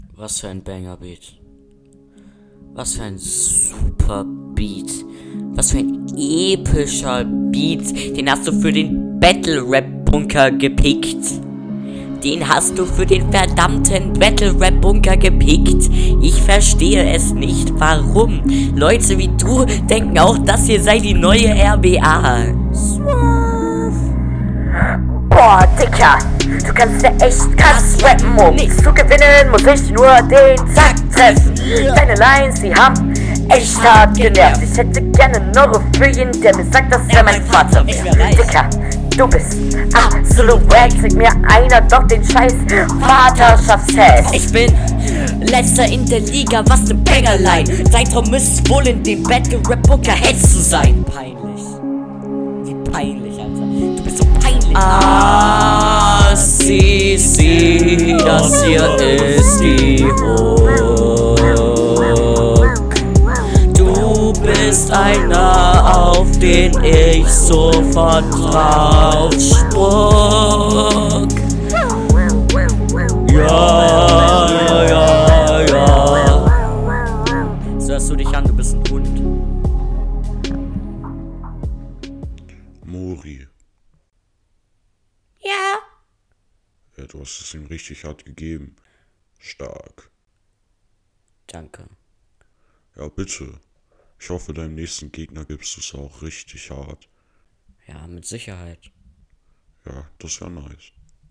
Outro ist iwie lahm. Da war für mich absolut kein Flow und kein Hörgenuss vorhanden.